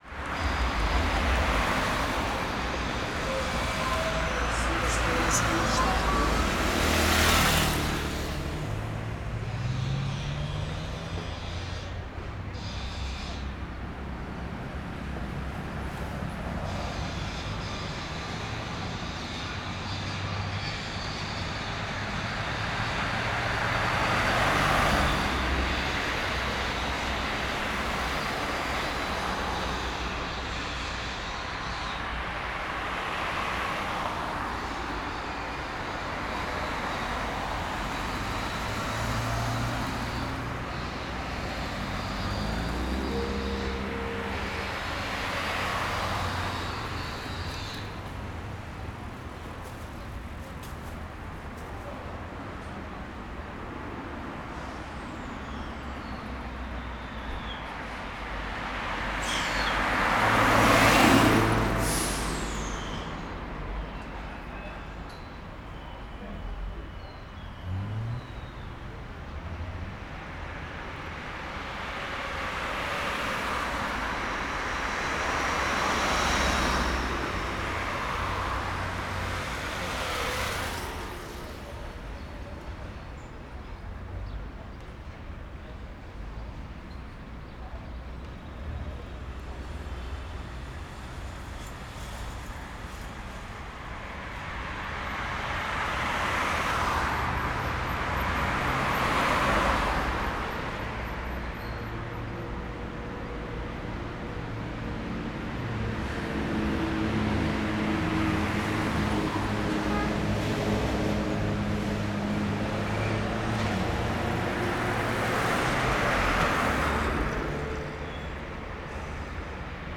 CSC-04-170-OL- Avenida com carros passando moto buzina furadeira.wav